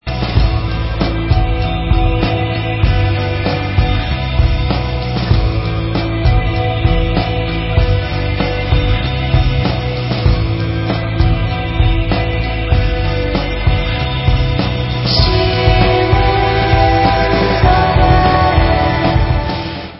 POP WAVE